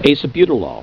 Pronunciation
(a se BYOO toe lole)